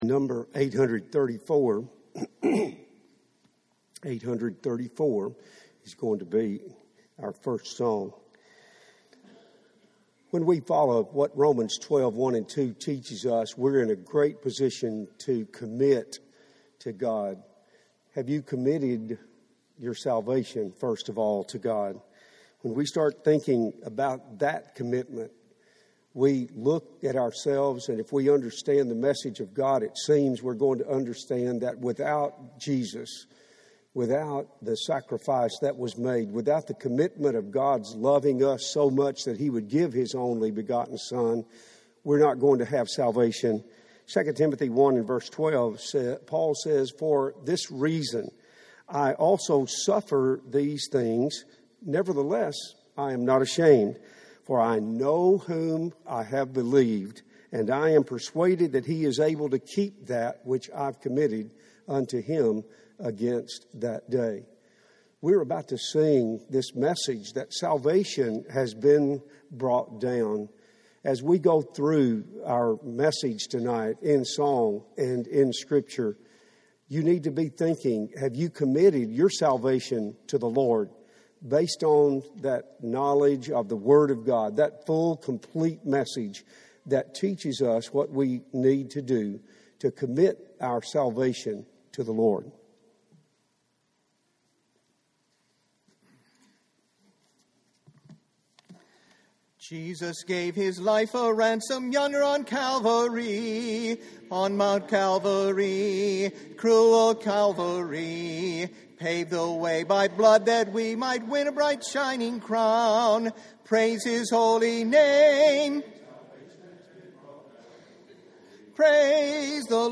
A sermon in scripture and song on what we are to commit to God.